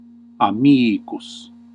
Ääntäminen
Synonyymit fidēlis Ääntäminen Classical: IPA: /aˈmiː.kus/ Haettu sana löytyi näillä lähdekielillä: latina Käännös Ääninäyte Adjektiivit 1. welcome US UK Substantiivit 2. friend US UK 3. boyfriend US Suku: m .